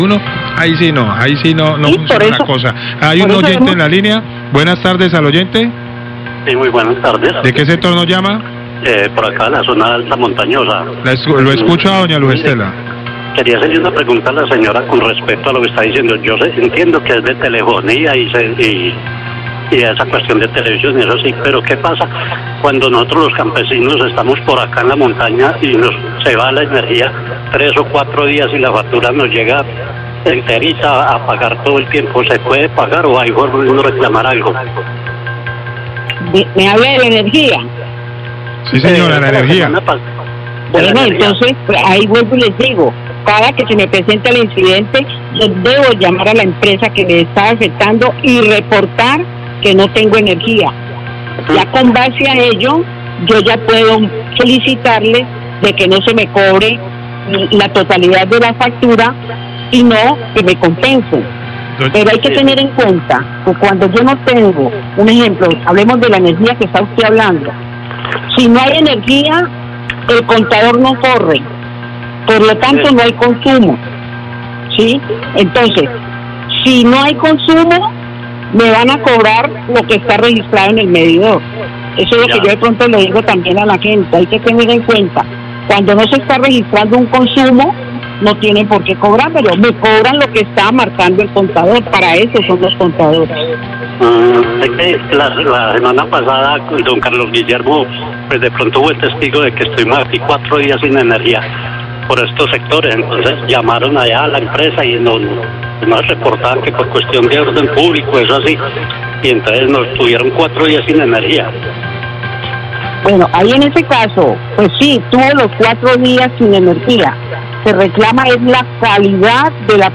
Radio
queja oyentes